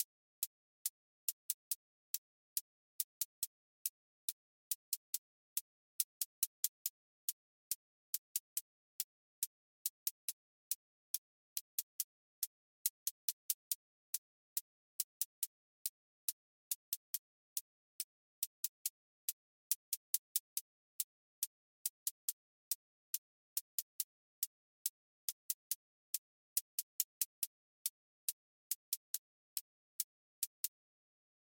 Trap 808 tension with clipped hats
Half Time Bounce QA Listening Test trap Template: trap_hats_a April 21, 2026 ← Back to all listening tests Audio Half Time Bounce